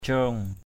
/cro:ŋ/ (đg.) để lên, gác lên = poser sur. crong laiy ngaok paban _cU t=k d} pbN gác chân lên bàn. pok gaok crong tagok ging _F%K _g<K _cU t_gK...